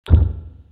sattelite hit